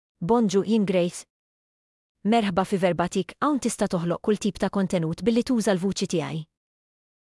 Grace — Female Maltese AI voice
Grace is a female AI voice for Maltese (Malta).
Voice sample
Female
Grace delivers clear pronunciation with authentic Malta Maltese intonation, making your content sound professionally produced.